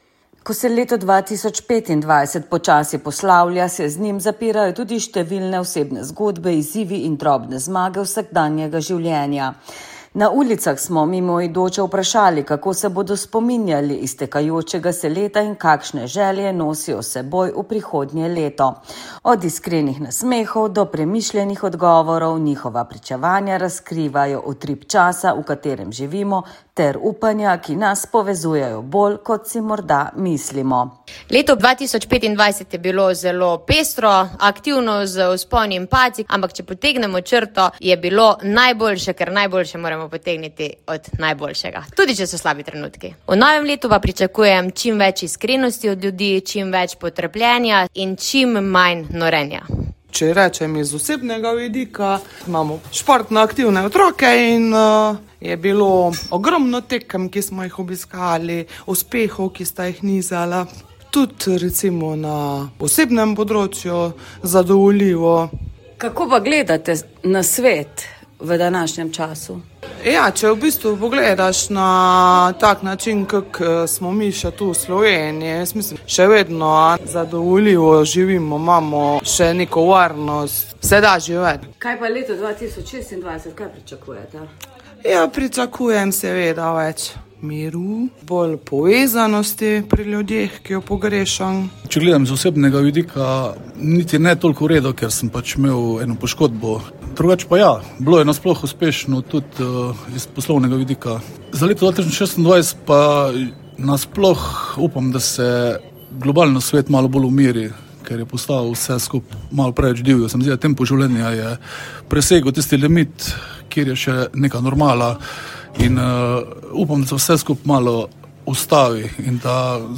Ko smo se na ulici ozrli nazaj proti letu 2025 in mimoidoče vprašali, kaj jim je prineslo ter kaj si želijo v 2026, so njihovi kratki, a povedni odgovori razkrili iskren utrip vsakdana in zaželeli dobro vsem.